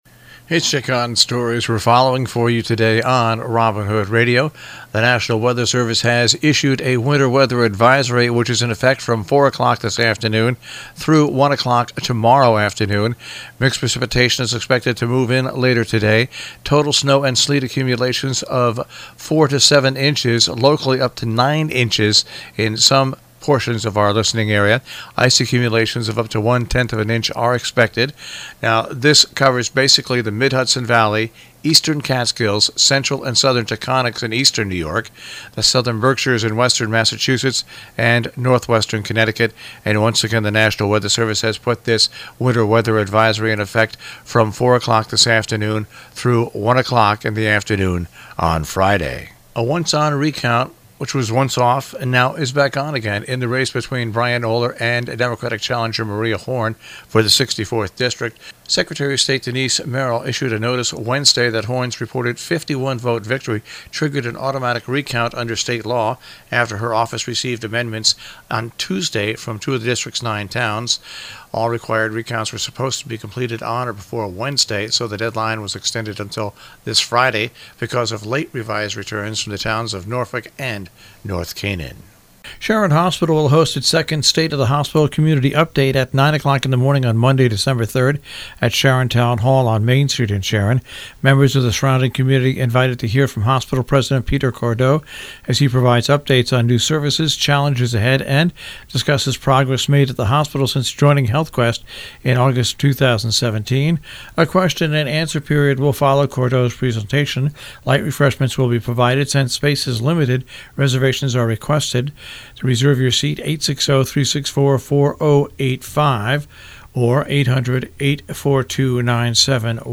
covers news and events in the Tri-State Region on The Breakfast Club on robin Hood radio